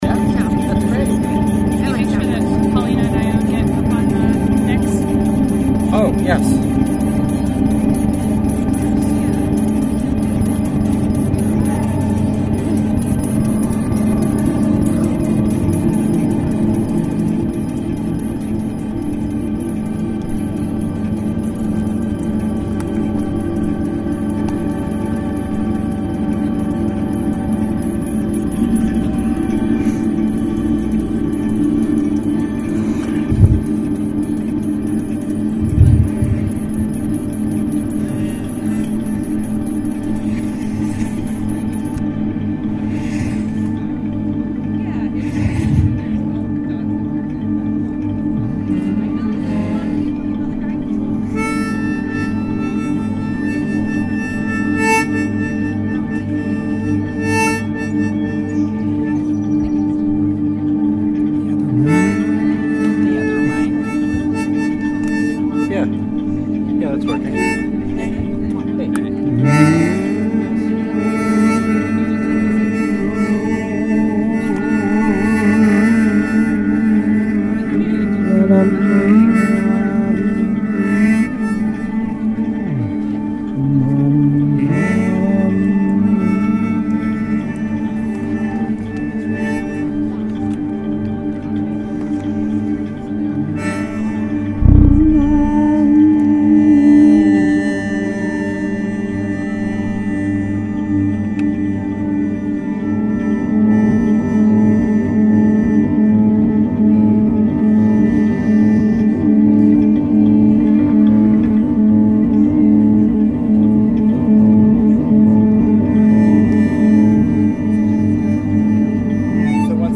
Audio Buffet Conclusion (ambient recording). (Audio)